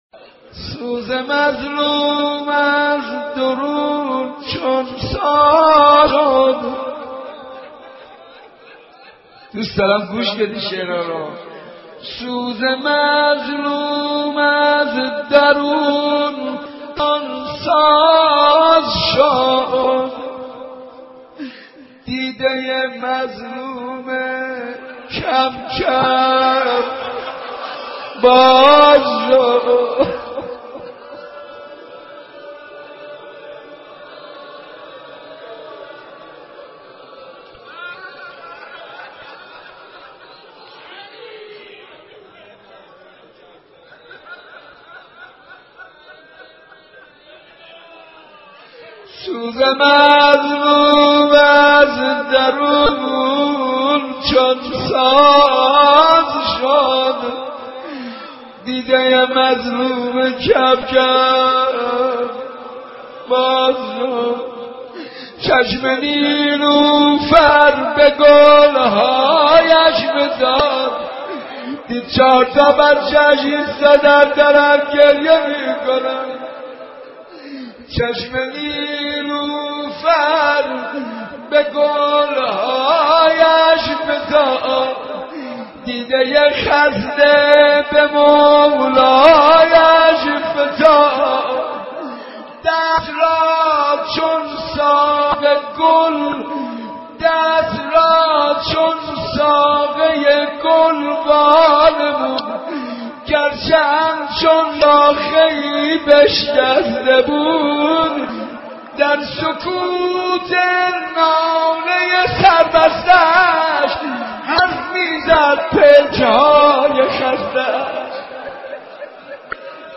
مداحی فاطمیه